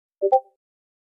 discord_sound.mp3